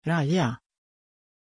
Aussprache von Raja
pronunciation-raja-sv.mp3